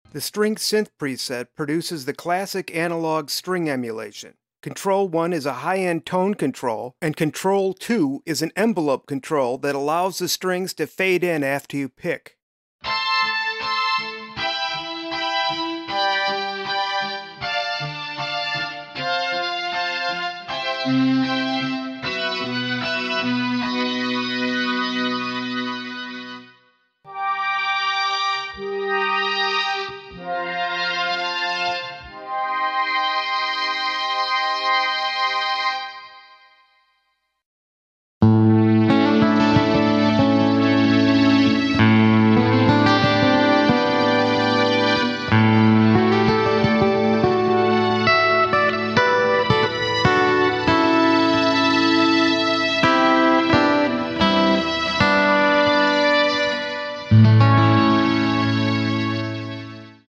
EHX SYNTH9 Synthesizer Machine Pedal